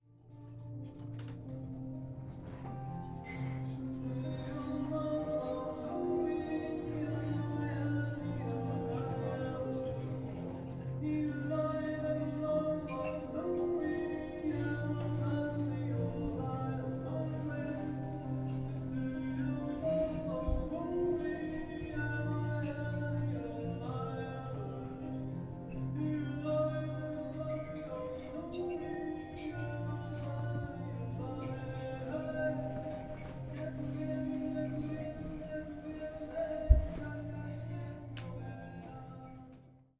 Bolo počuť iba občasný šepot, meditačnú melódiu a občasné štrnganie lyžičiek o steny hrnčekov.